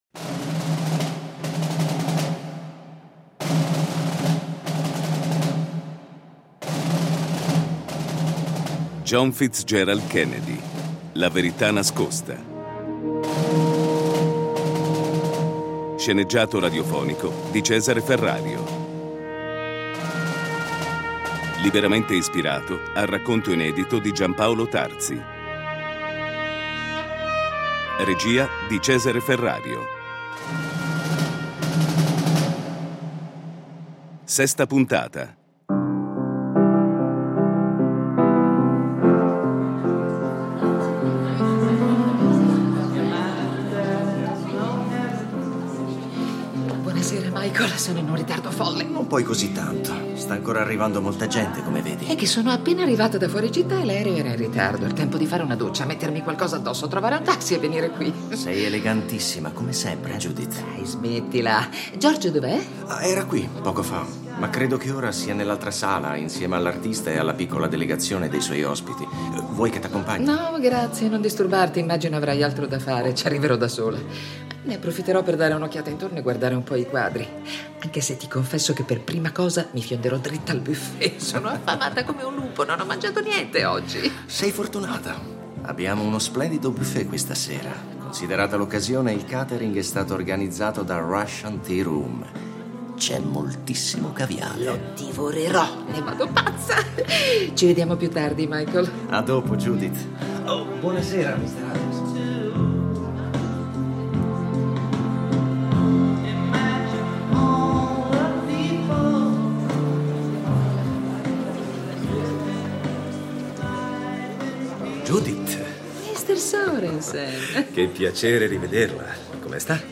Domande che, sorrette da una forte impalcatura drammaturgica e attraverso il potere fascinatorio ed evocativo di un racconto variegato, vergono rimbalzate all’attenzione dell’ascoltatore a cui spetterà il compito di trovare nuove risposte ai numerosi interrogativi.